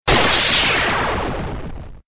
corexplode.mp3